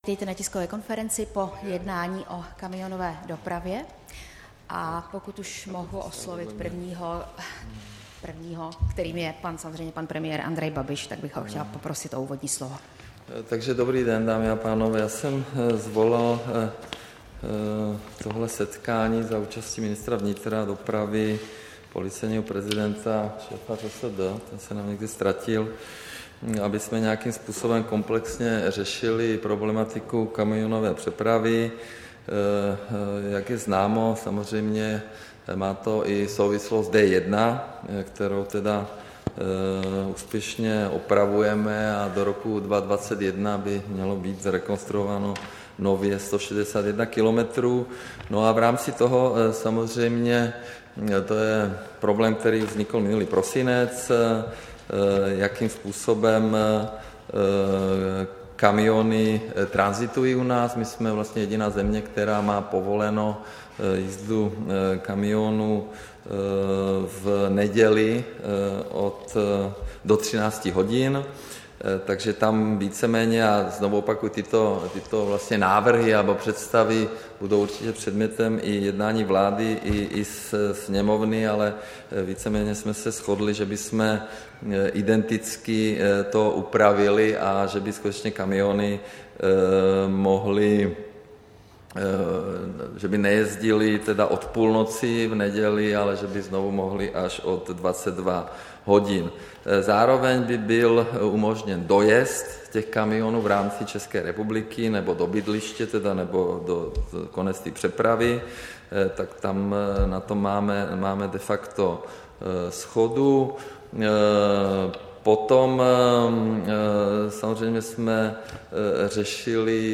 Tisková konference po jednání ke kamionové dopravě, 27. srpna 2019